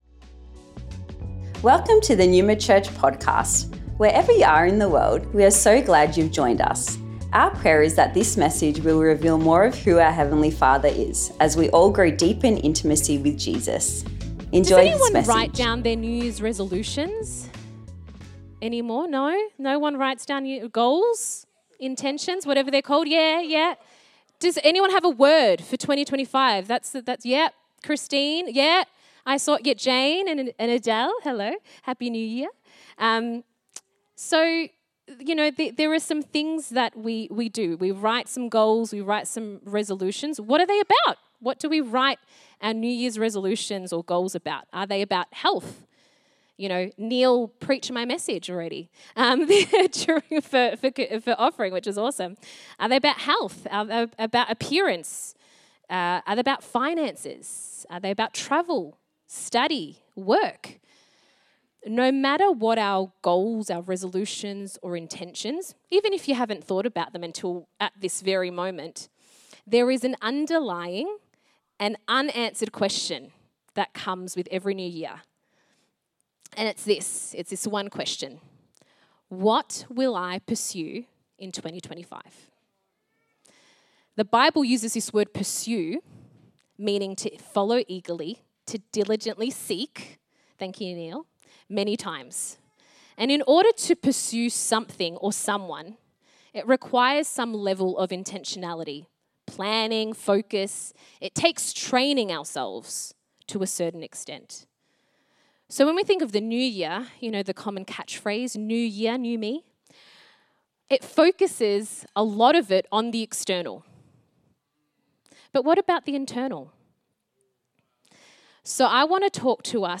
Originally recorded at Neuma Melbourne West